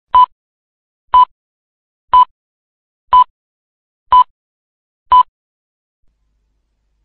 Звук пищит по радио СССР